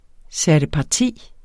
Udtale [ sæɐ̯dəpɑˈtiˀ ]